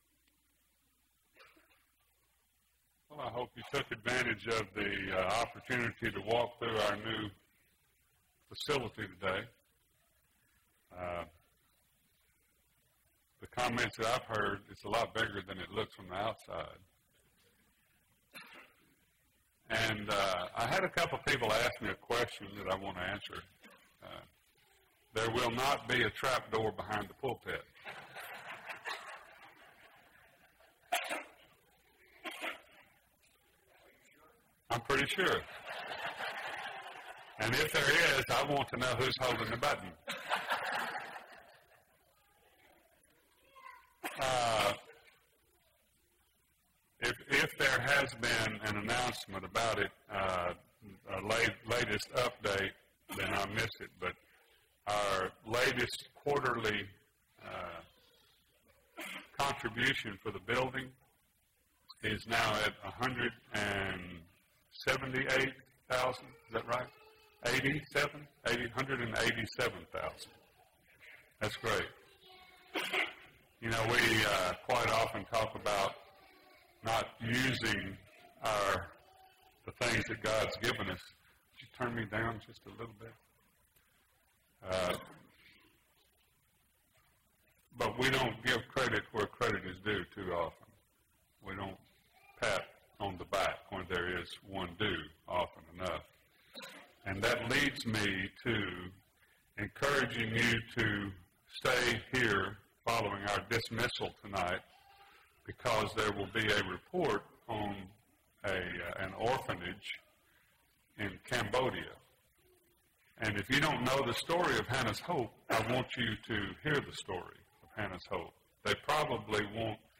Habakkuk – Bible Lesson Recording